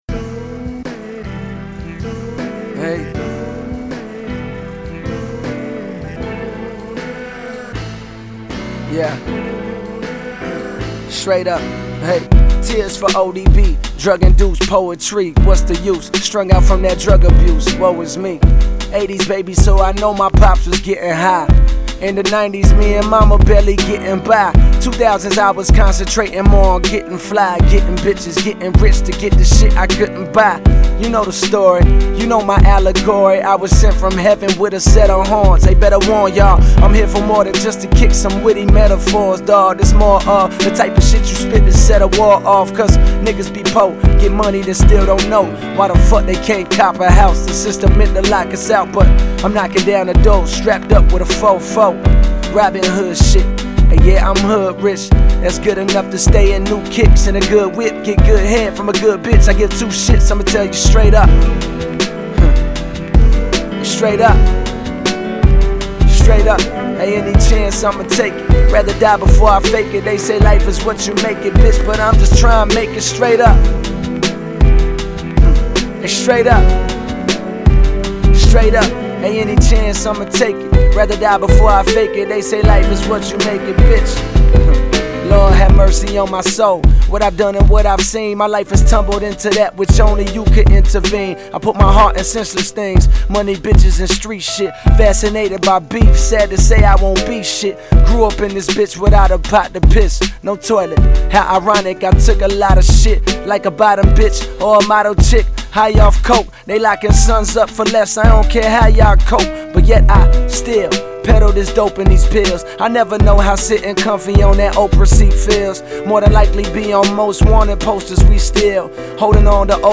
Genero: Hip-Hop/Rap